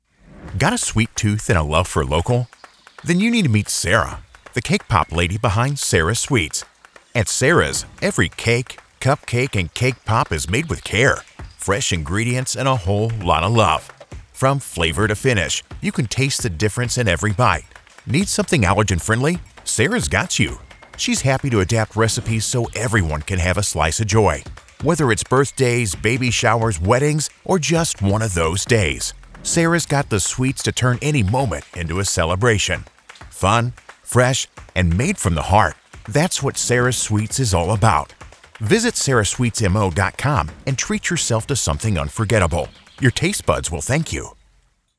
Young Adult
Middle Aged